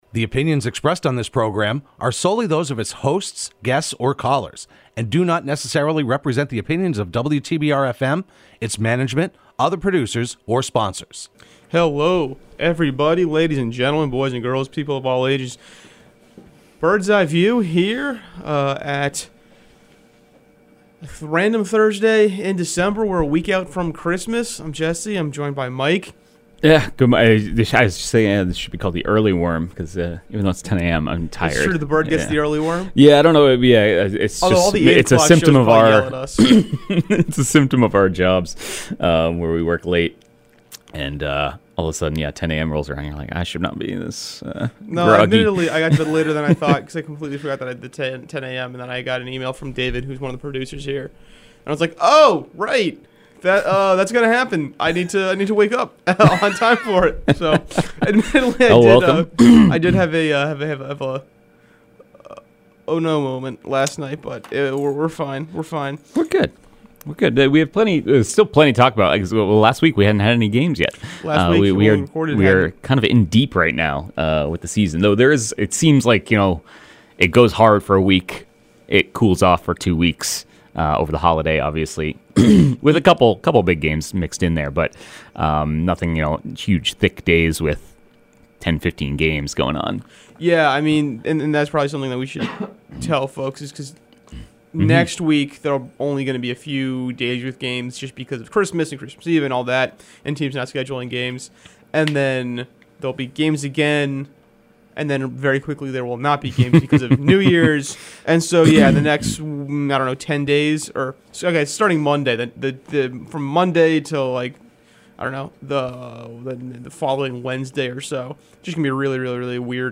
Hosted by the Berkshire Eagle Sports team and broadcast live every Thursday morning at 10am on WTBR.